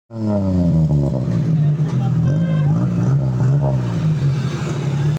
exhaust system in Suzuki Khyber.